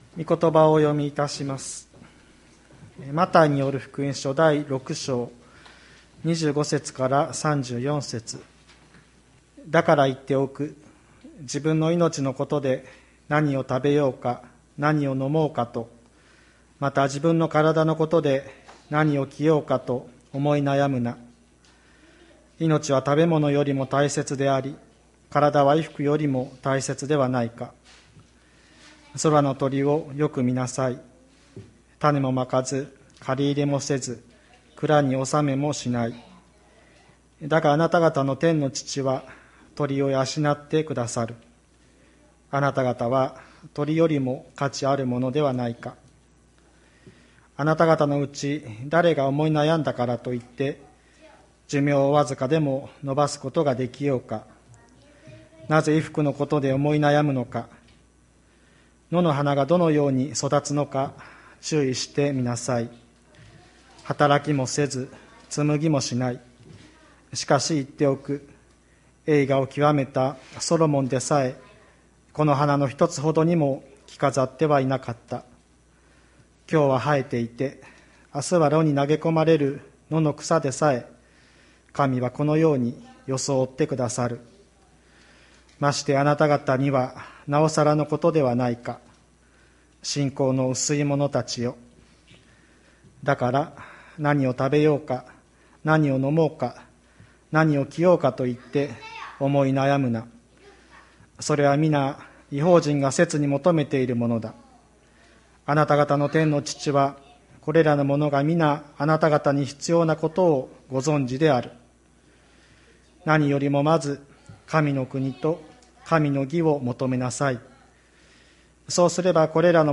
2021年01月10日朝の礼拝「思い悩まない秘訣」吹田市千里山のキリスト教会
千里山教会 2021年01月10日の礼拝メッセージ。 主イエスがここで何度も繰り返される言葉は、「思い悩むな」ということです。